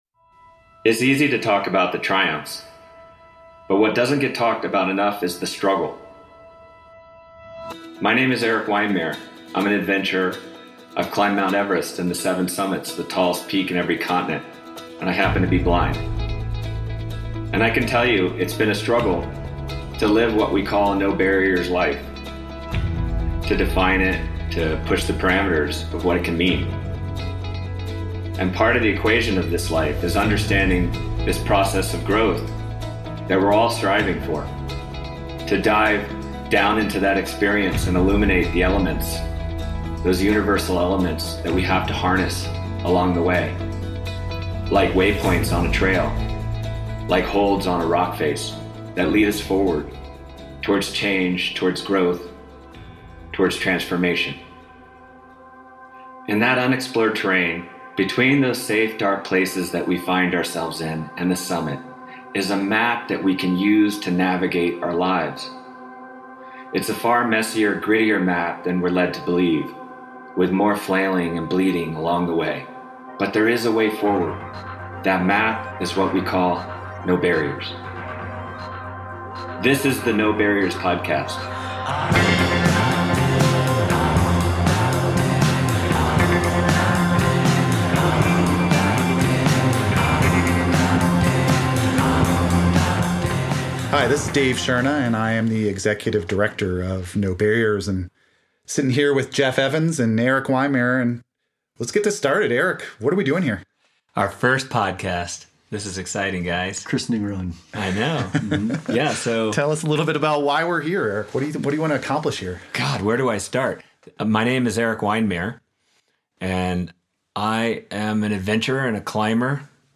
Seeking Truth & Meaning: Talking to the Director of the Colorado Outdoor Recreation Industry Office, Luis Benitez